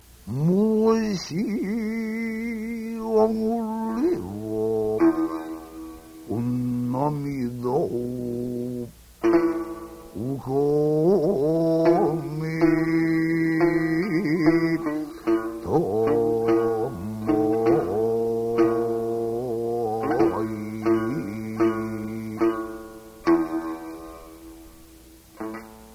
豊沢広助 三味線の手 節と手順